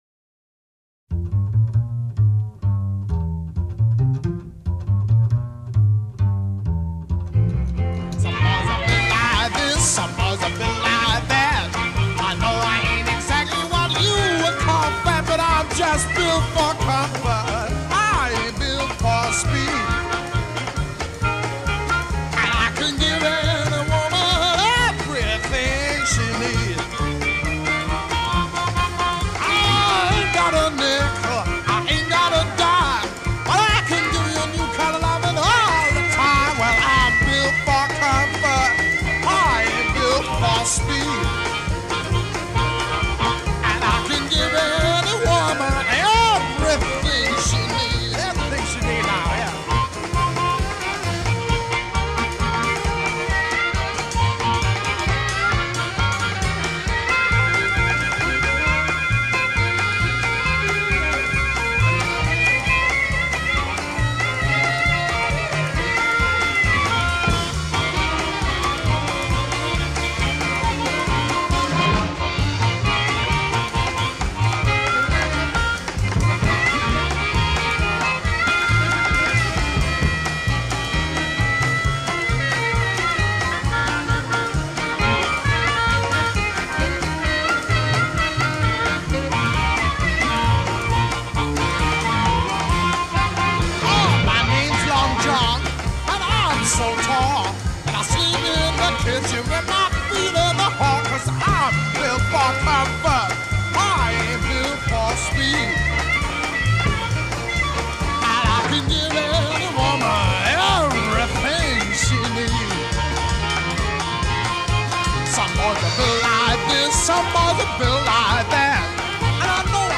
guitar
drums
tenor saxophone
harmonica
intro 0:00 4 begin with bass
A verse 0: 12 vocal solo over ensemble a
A verse : 13 as above with stop time at end a